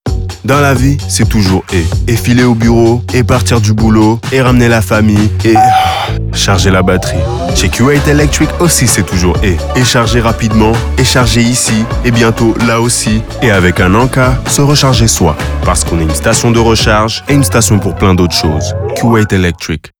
La musique et le sound design font partie d'un nouveau sonic identity et ont été créés par Sonhouse.
Q8 Electric Radio 20" FR.wav